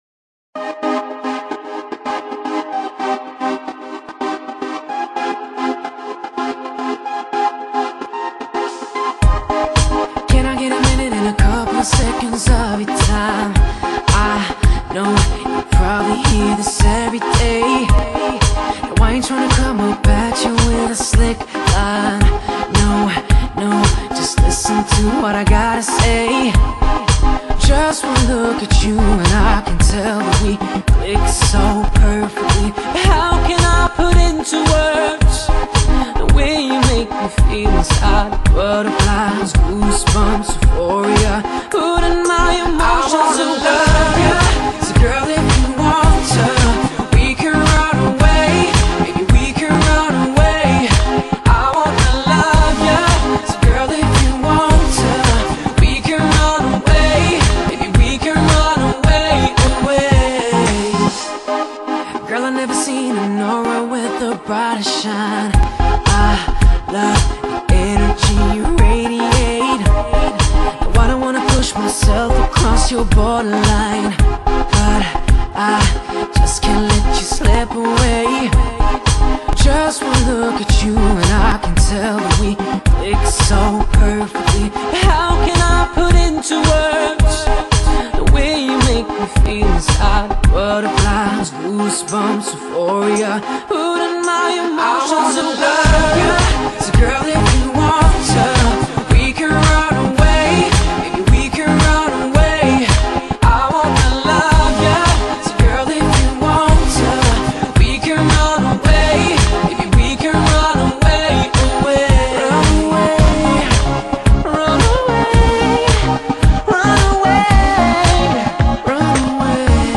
整張專輯以流行電子舞曲為主，搭配抒情R&B的曲風，充滿了無盡的魅惑力